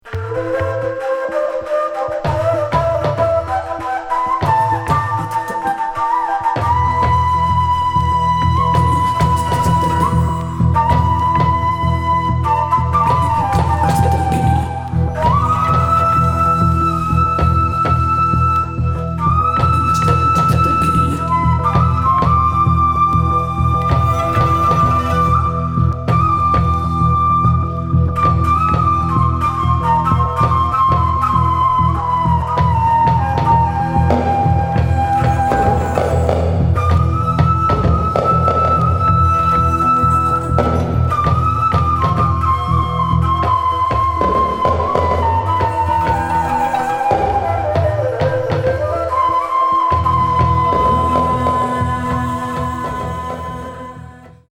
メタルパーカション系の打撃音も加わって全編ただごとじゃない雰囲気。
空想民俗　第四世界